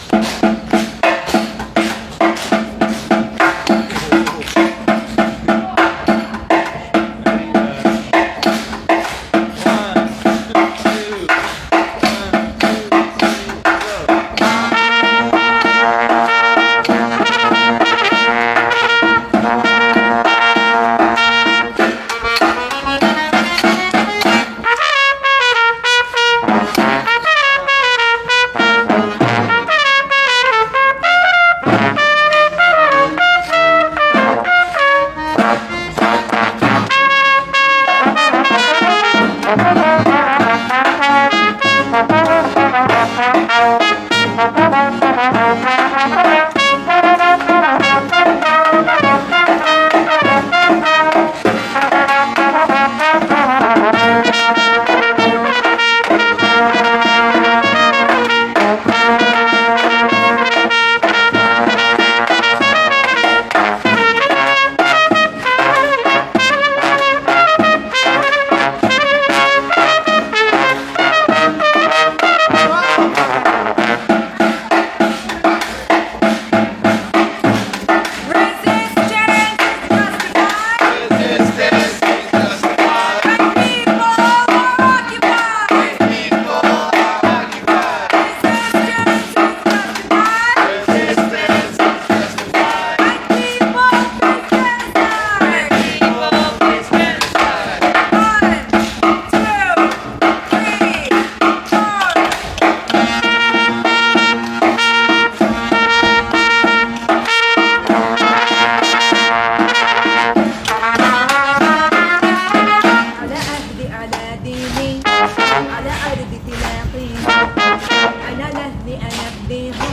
Form/Arrangement Notes Starts with dabke beat: |D-D-D-T-D--T--T-| Intro - on Cue A - Melody part has the main melody w/stops (when returning to A from D, play w/o stops over Malfuf: |D--T--T-|.
Rehearsals